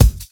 Kick Flamingo 3.wav